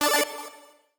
UIClick_Retro Delay 03.wav